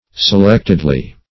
Se*lect"ed*ly